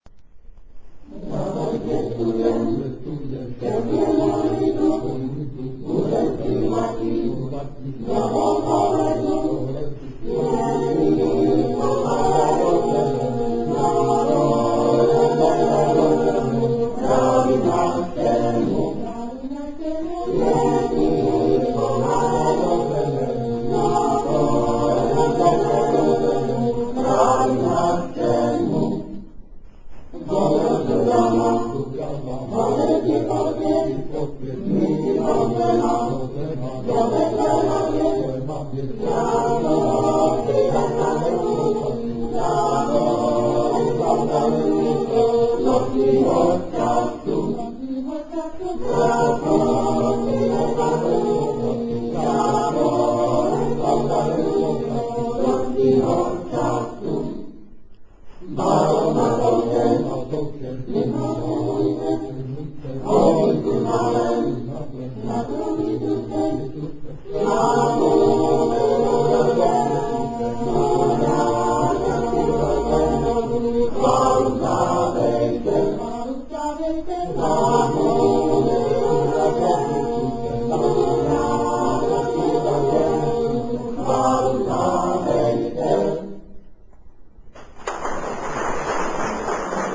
18. komorní koncert na radnici v Modřicích
Vybrané třebíčské koledy (texty) - amatérské nahrávky ukázek: